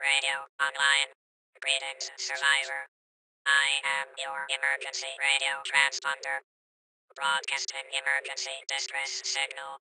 RadioBootup.ogg